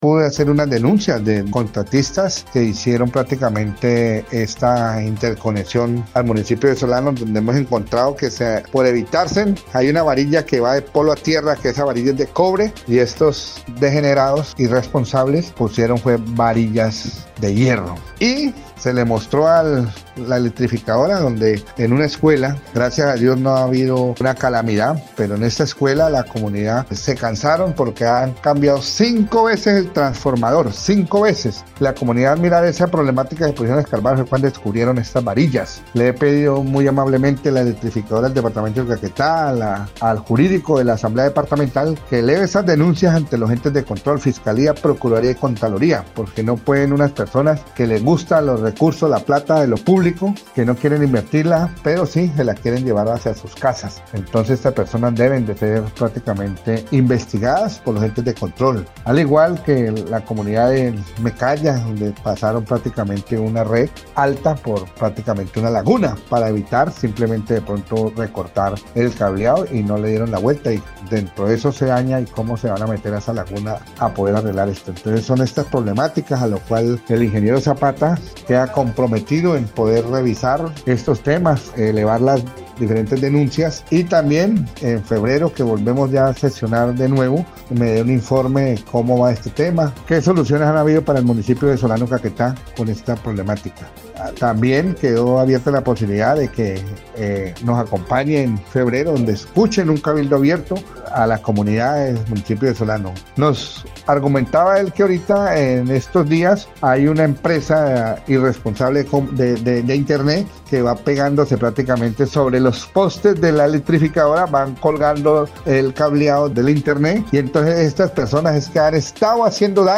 La denuncia la hizo el diputado por el partido de la U, Alfredo Silva Neira, quien dijo que, en uno de los casos, los contratistas cambiaron las varillas para la obra, lo cual generó que un transformador se halla dañado en cinco oportunidades.
02_DIPUTADO_ALFREDO_SILVA_DENUNCIA.mp3